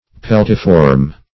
Search Result for " peltiform" : The Collaborative International Dictionary of English v.0.48: Peltiform \Pel"ti*form\, a. [Pelta + -form.]